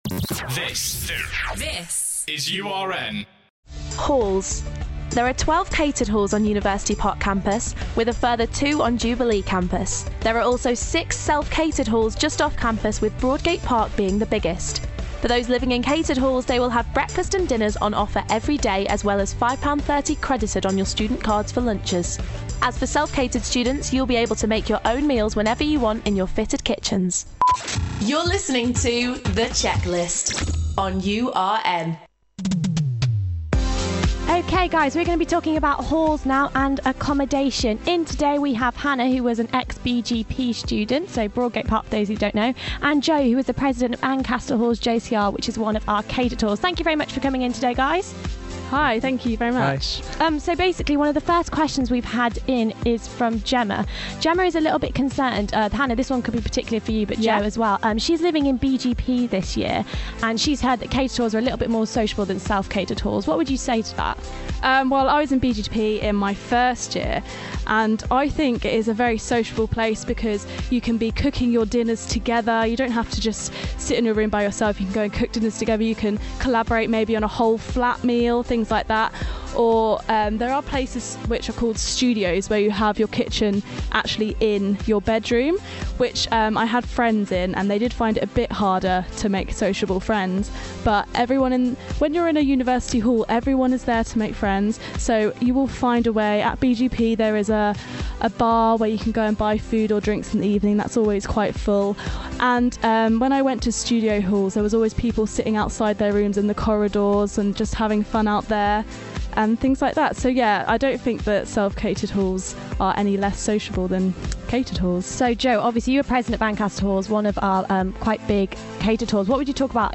spoke to uni students about Hall life at Uni